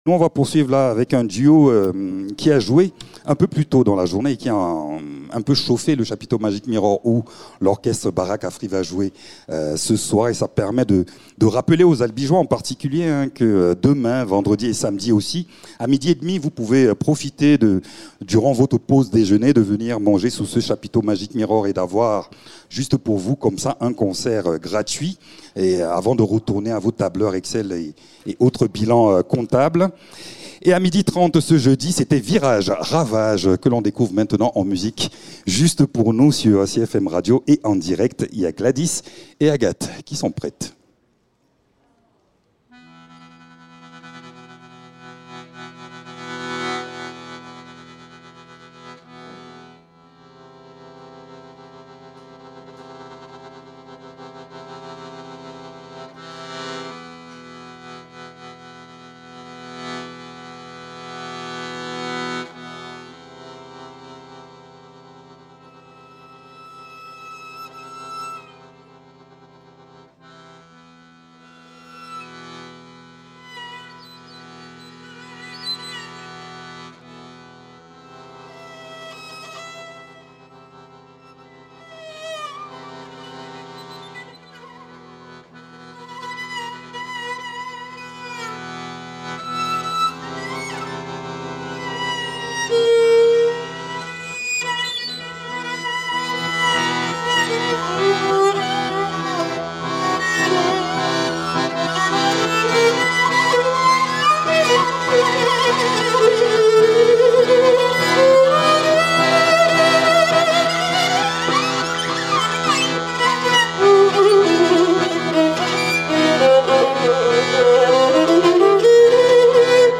C’est la rencontre entre la gadulka et l’accordéon dans l’improvisation, mais aussi entre la musique traditionnelle bulgare, le free jazz, le chant, la musique répétitive ...